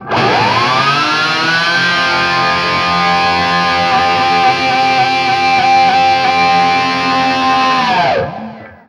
DIVEBOMB11-R.wav